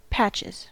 Ääntäminen
Ääntäminen US Haettu sana löytyi näillä lähdekielillä: englanti Käännöksiä ei löytynyt valitulle kohdekielelle. Patches on sanan patch monikko.